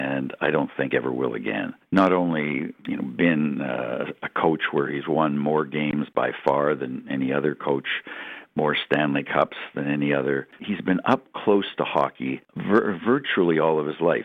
In an interview with Quinte News, Dryden talks about how amazing his coach was.  He says Bowman was unlike another other hockey mind that existed.